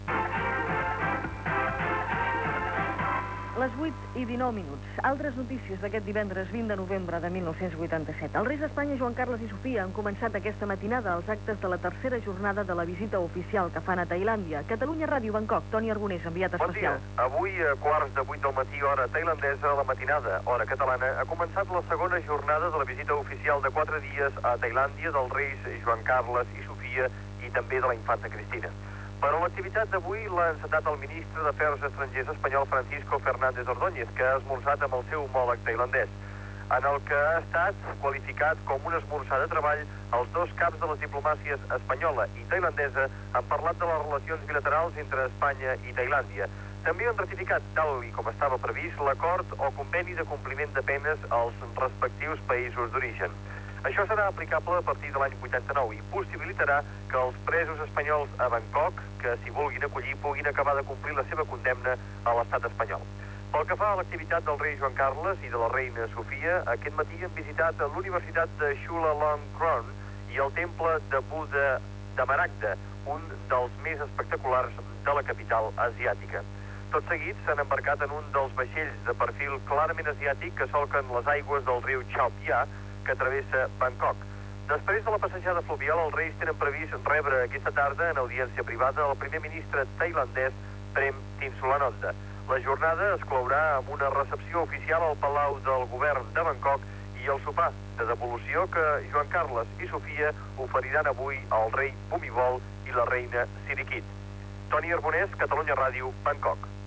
Sintonia de la ràdio, hora, data, informació, des de Bangkok, del tercer dia de la visita dels reis d'Espanya Juan Carlos I i Sofia a Tailàndia
Informatiu